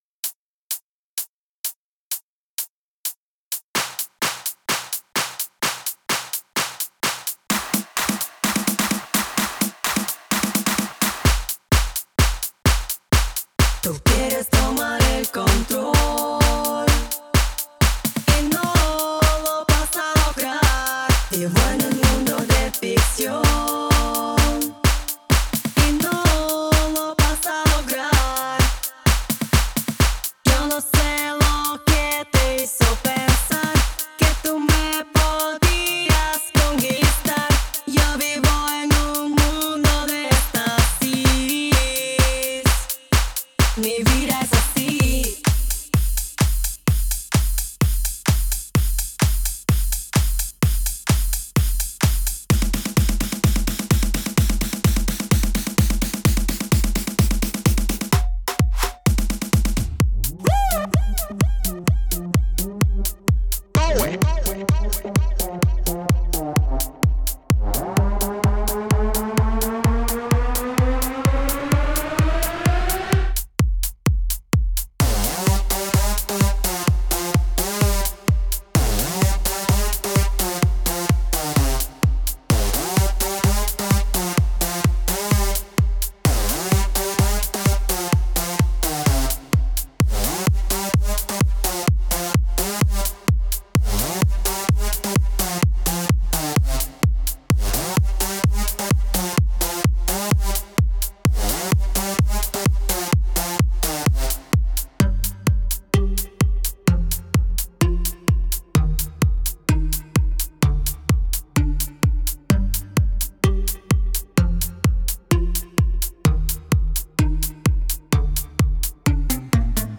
Electronic
Progressive House
Deep House
Mood: Party Music